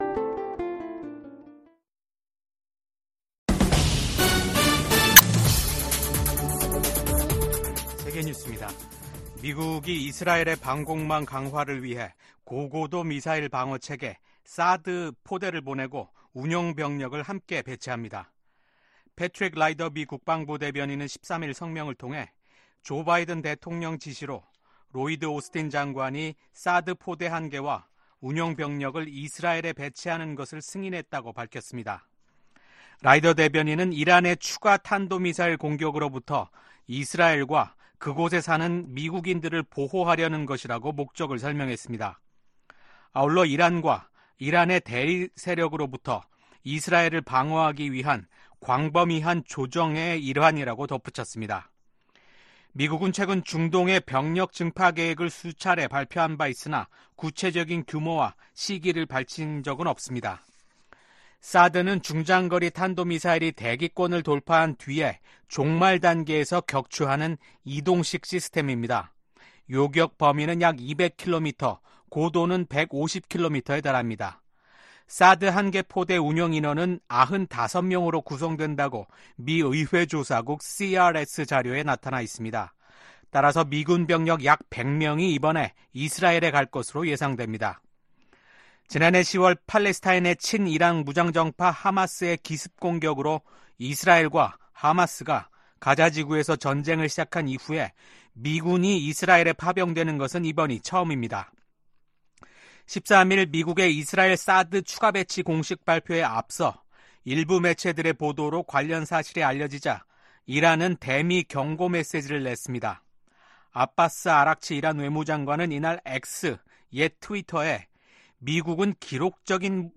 VOA 한국어 아침 뉴스 프로그램 '워싱턴 뉴스 광장' 2024년 10월 15일 방송입니다. 북한이 한국 측 무인기의 평양 침투를 주장하면서 한국과의 접경 부근 포병 부대들에게 사격 준비 태세를 지시했습니다. 미국 북한인권특사는 북한에서 공개재판과 공개처형이 늘어나는 등 북한 인권 실태가 더욱 열악해지고 있다고 지적했습니다.